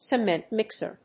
アクセントcemént mìxer